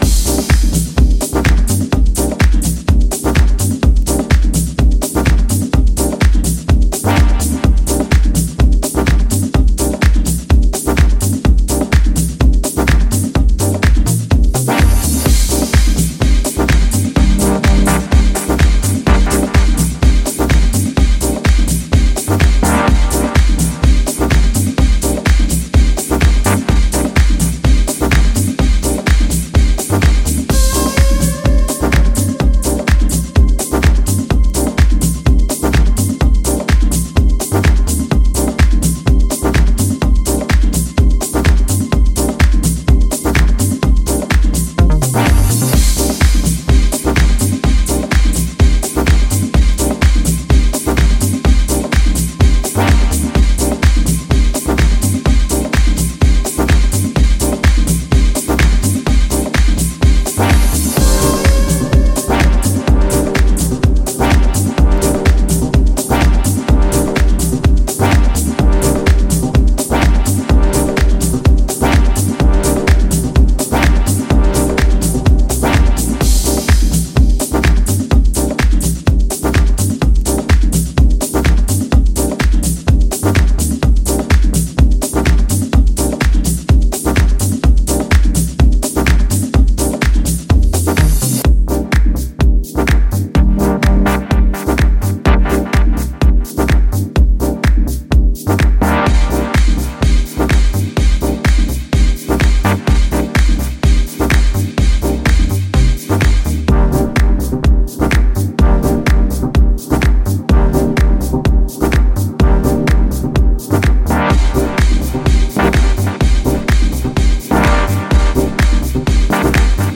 deep house
a percussion-driven peak-time weapon